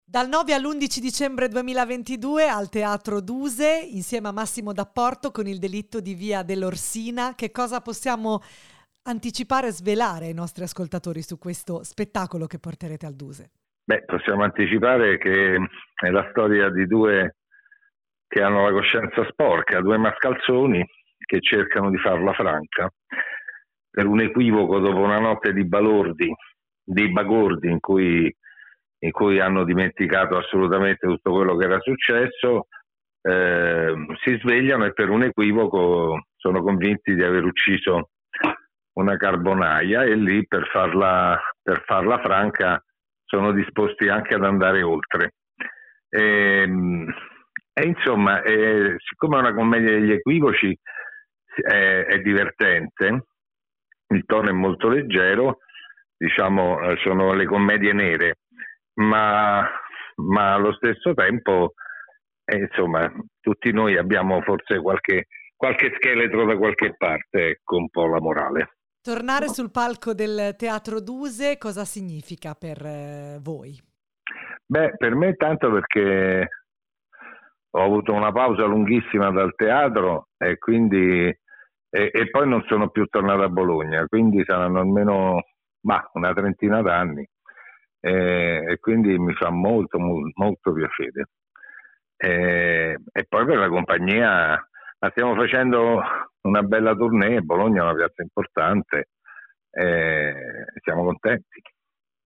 Home Magazine Interviste Massimo Dapporto e Antonello Fassari presentano “Il delitto di via dell’Orsina”